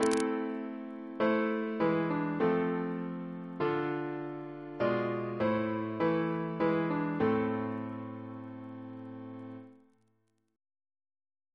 CCP: Chant sampler
Single chant in A minor Composer: William Hayes (1707-1777) Reference psalters: ACB: 89; PP/SNCB: 77